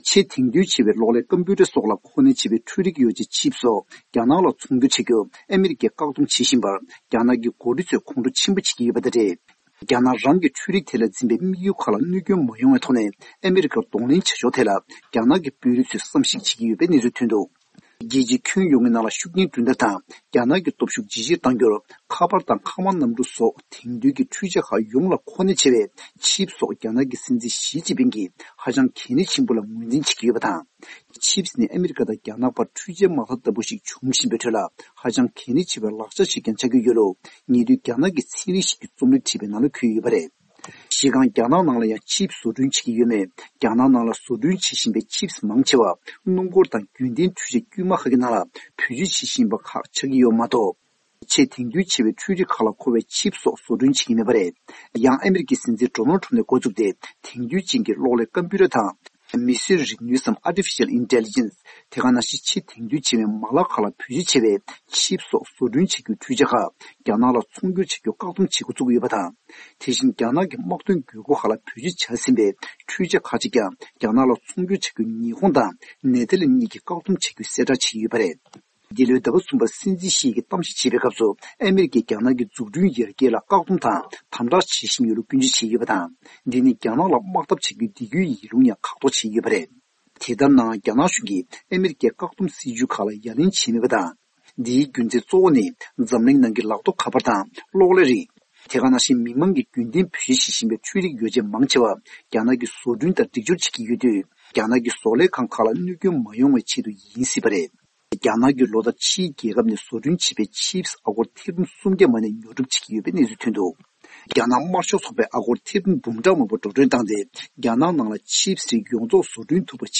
གནས་ཚུལ་སྙན་སྒྲོན་ཞུས་པ་འདི་གསན་རོགས་གནང་།